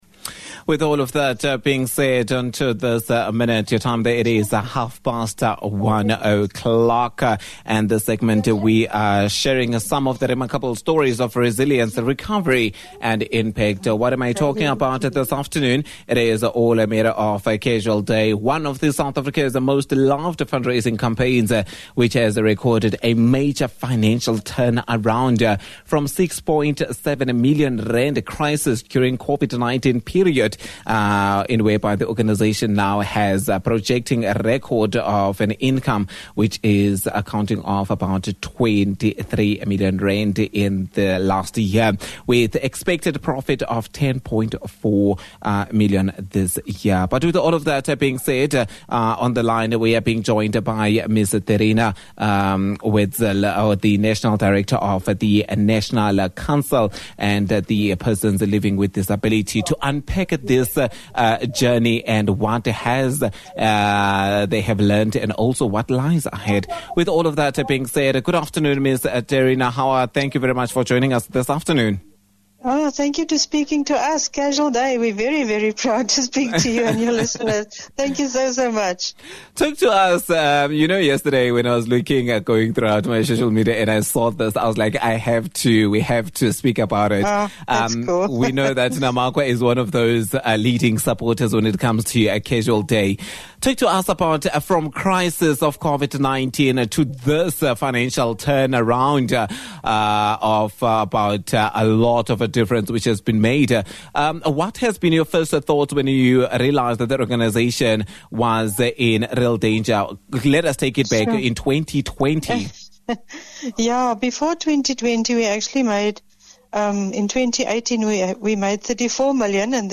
Attachments CASUAL DAY INTERVIEW.mp3.mpeg (10 MB)